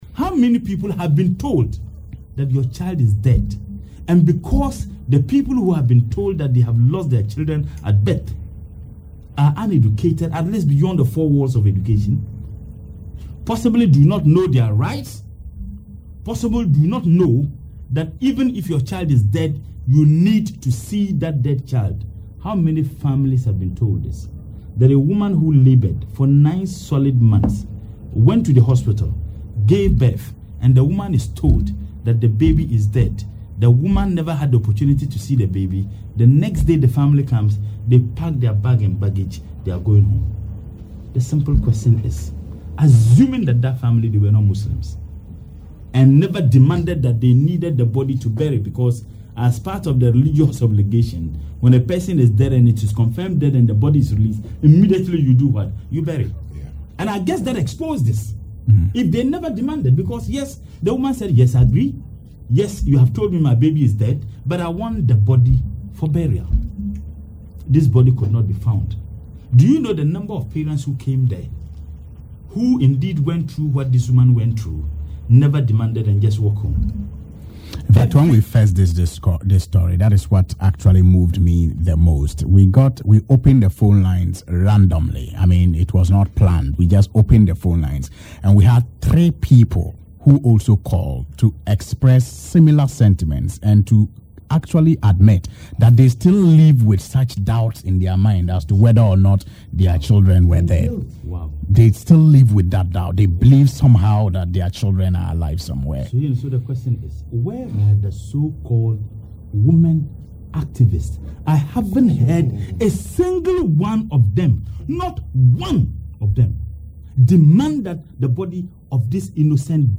Murtala Mohammed made the demand on Radio Gold’s Alhaji and Alhaji programme while contributing to a discussion on the mysterious disappearance of the baby thought to have been given a stillbirth and the subsequent altercation between some youth of the Zongo community in Kumasi and a section of workers of the hospital.